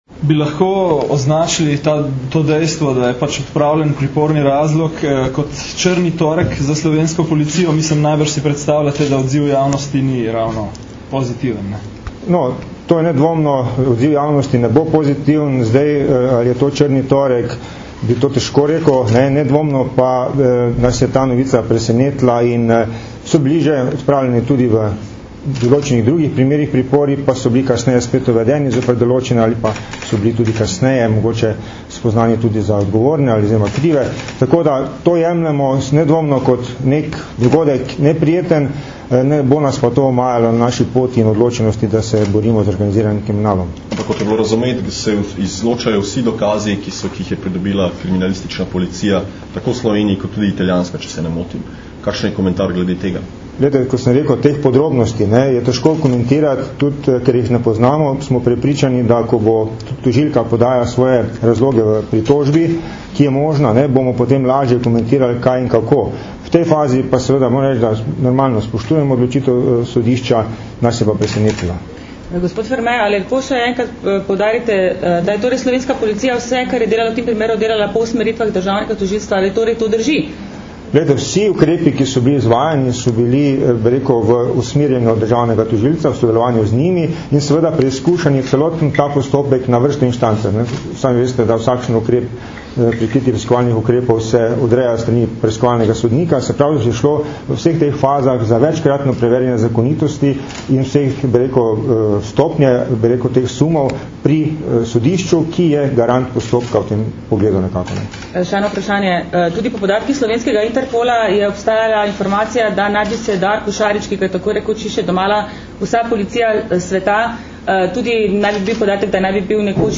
Novinarska vprašanja in odgovori (mp3)